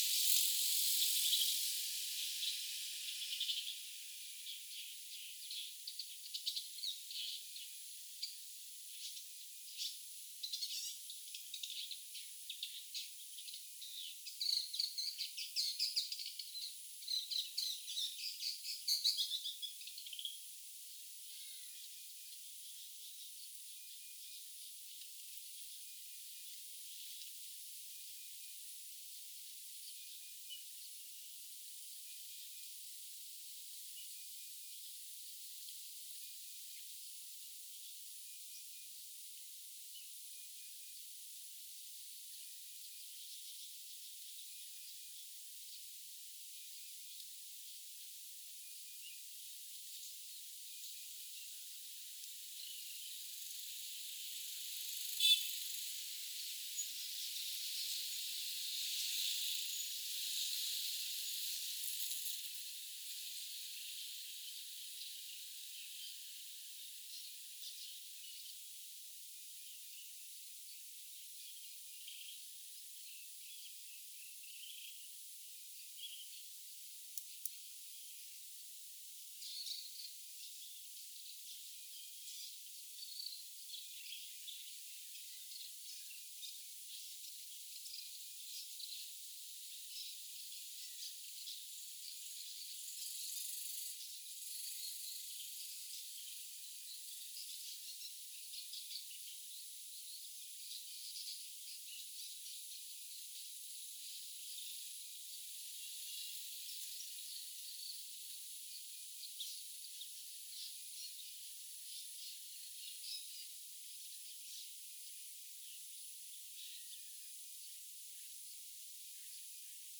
Demonstration soundscapes
anthropophony
biophony
transportation